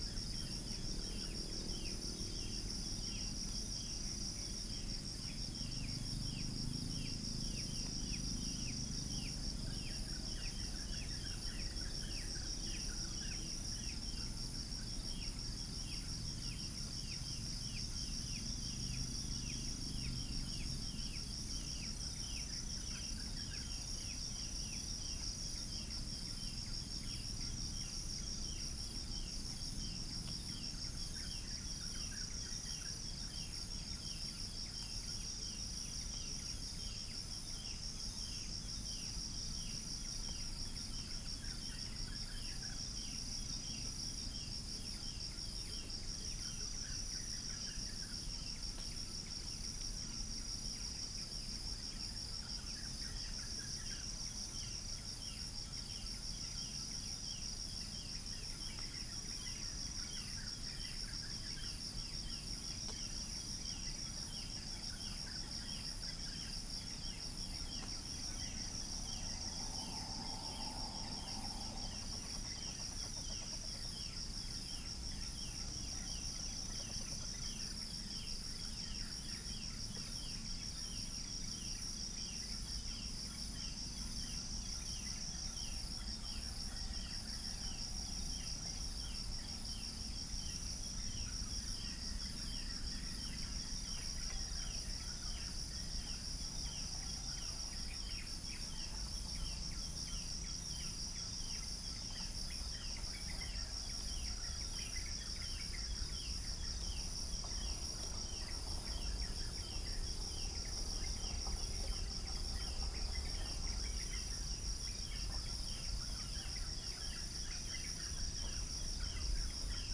Upland plots dry season 2013
Geopelia striata
Centropus sinensis
Pycnonotus goiavier
Orthotomus sericeus
Mixornis gularis
Prinia familiaris
Copsychus saularis